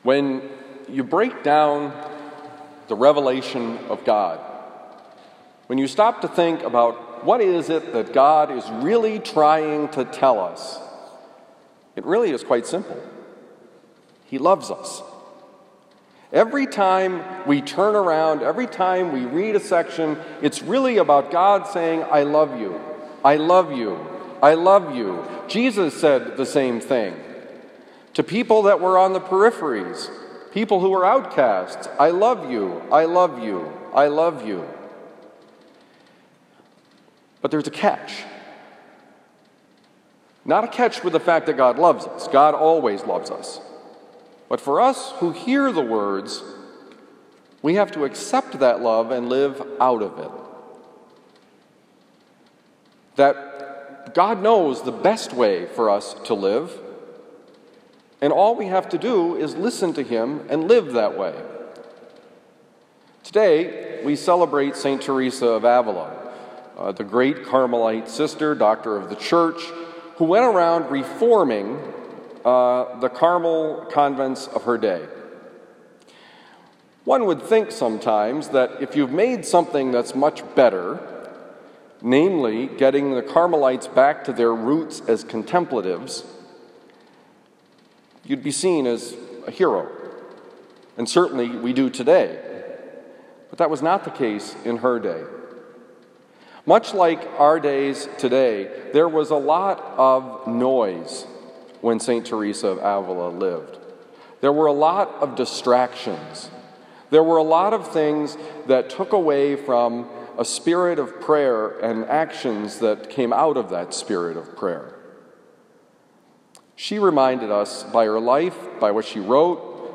Homily for October 15, 2020
Given at Christian Brothers College High School, Town and Country, Missouri.